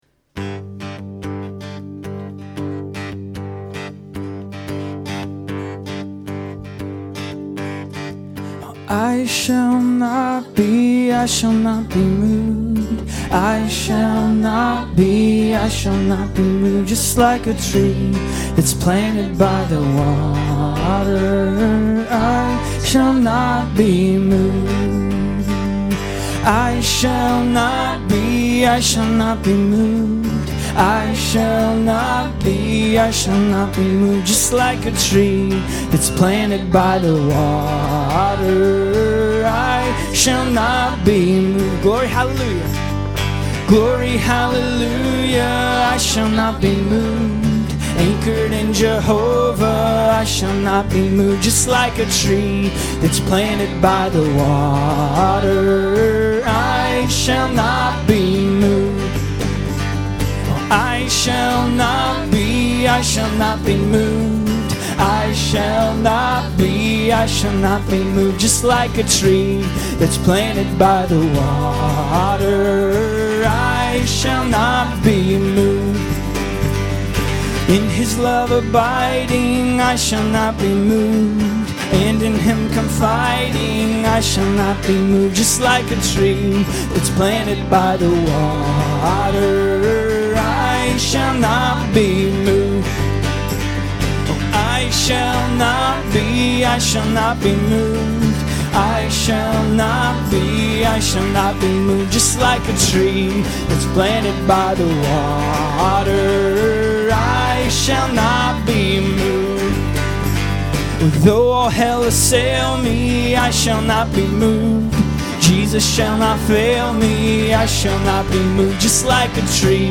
This week we had an all age choir and we had a blast with this song. I love to hear the saints—young and old, sing out!